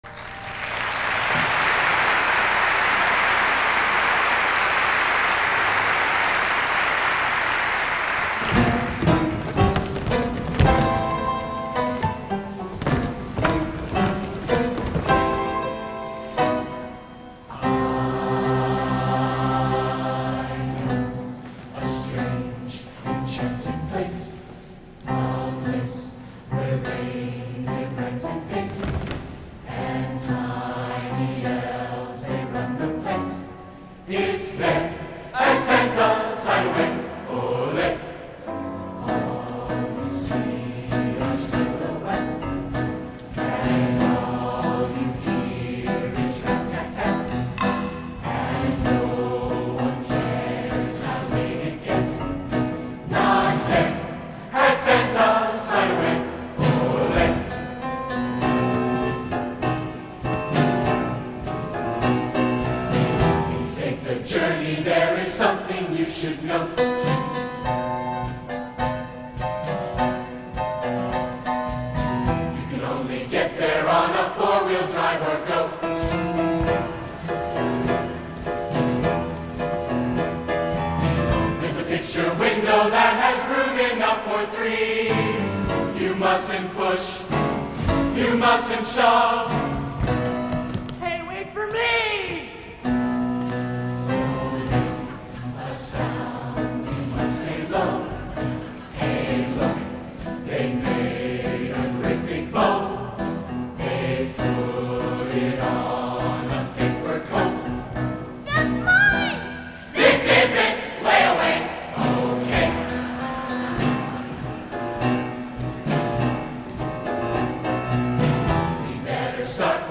Location: West Lafayette, Indiana
Genre: | Type: Christmas Show |